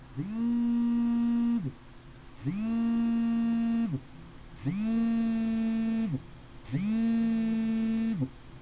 iphone " 移动振动
描述：电话响了
标签： 打电话 手机 振动 手机 电话 铃声
声道立体声